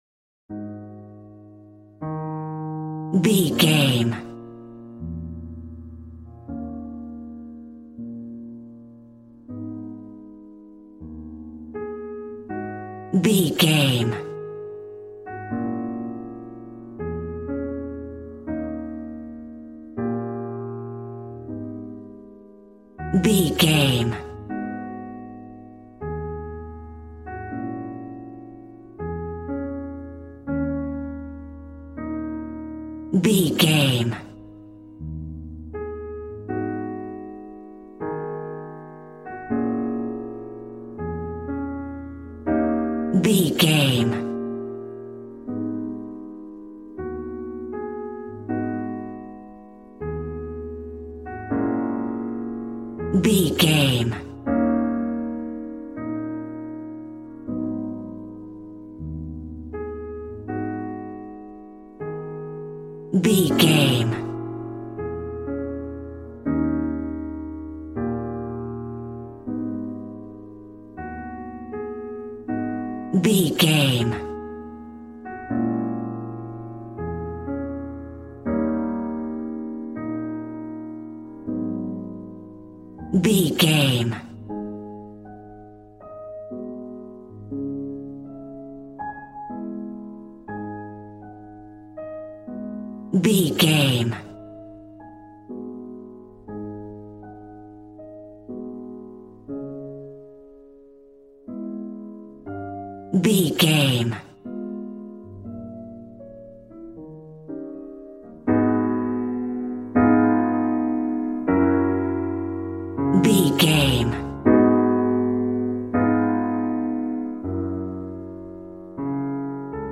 Smooth jazz piano mixed with jazz bass and cool jazz drums.,
Aeolian/Minor
A♭